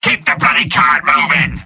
Robot-filtered lines from MvM. This is an audio clip from the game Team Fortress 2 .